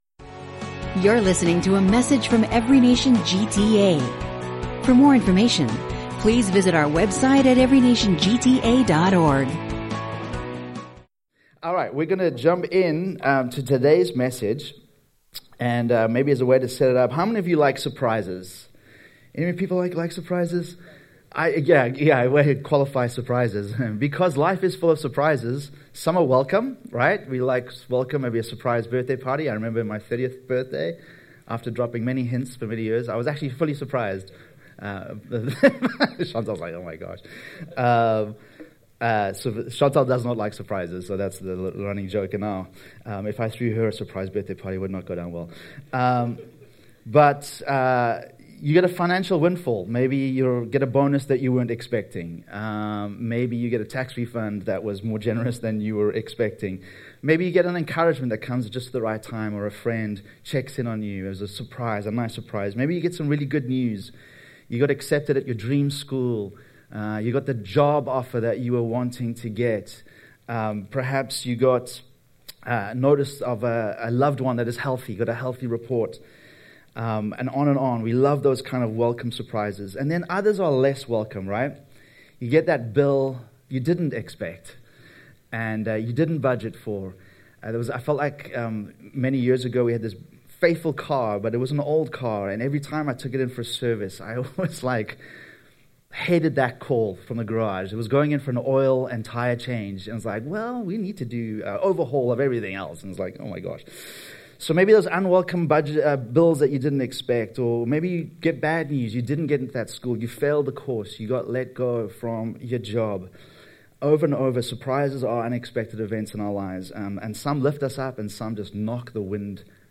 This sermon highlights how joy can unexpectedly flourish and support us during life’s barren, dark, or difficult times.